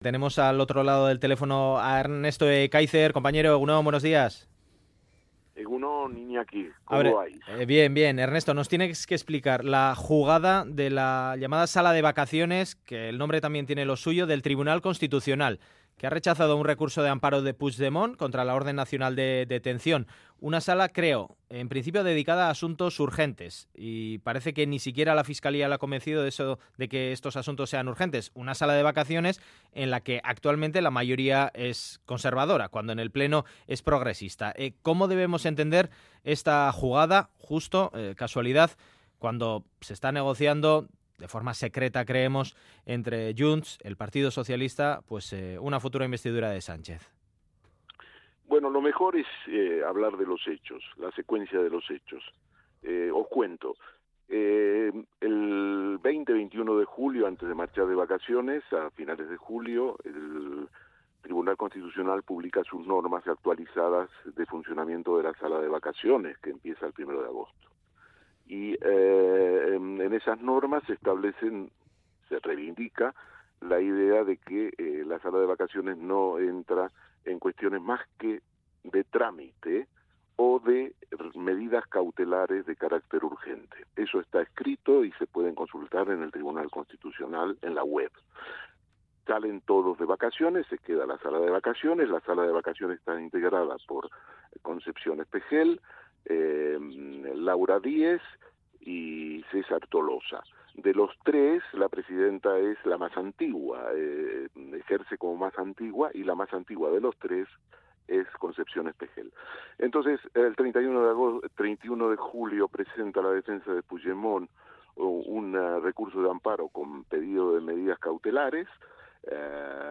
El periodista Ernesto Ekaizer denuncia un chanchullo en el Constitucional en el tema Puigdemont